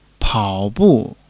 pao3--bu4.mp3